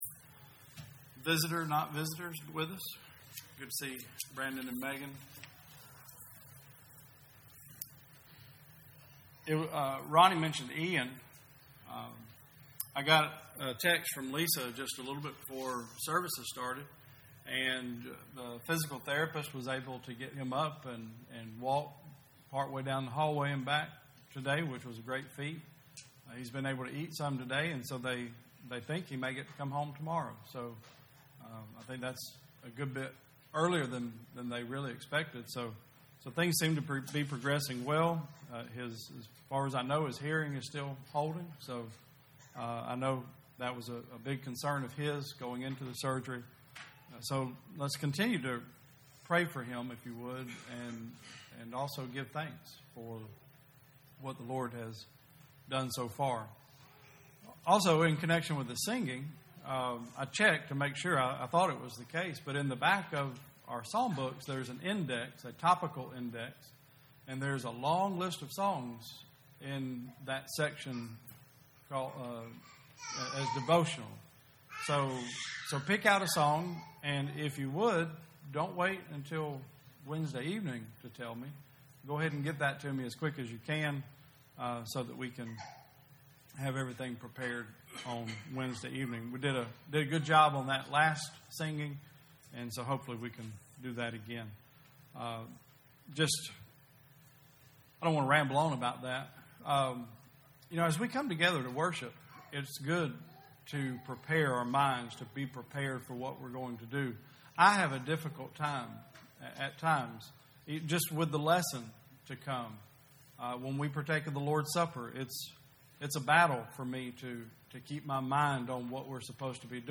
2019 Service Type: Sunday Service Preacher